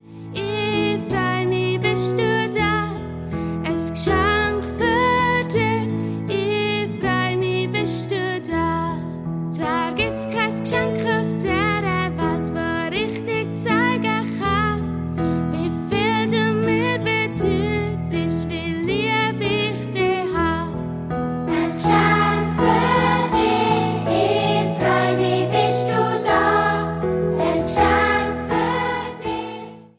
Weihnachtsmusical